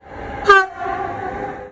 Horn & fløjter
EB Tyfon (direkte lydlink)
Langeskov St.
eb_tyfon2.mp3